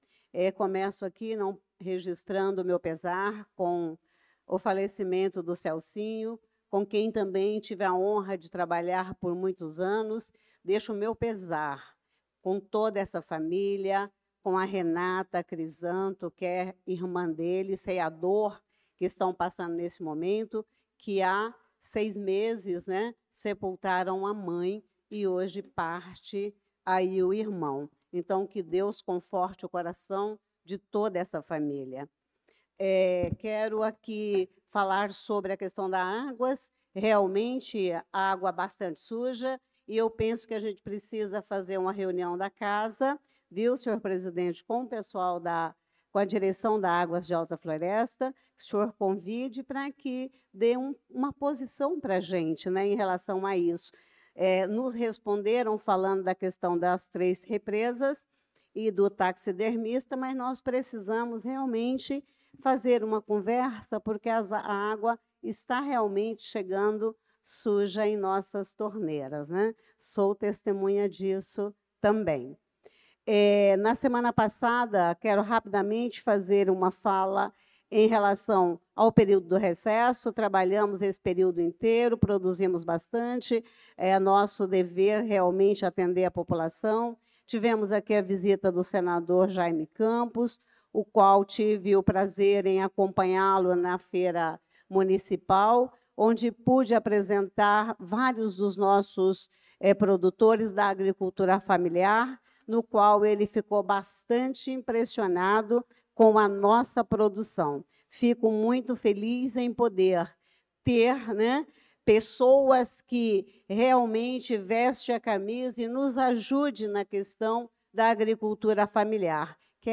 Pronunciamento da vereadora Elisa Gomes na Sessão Ordinária do dia 04/08/2025.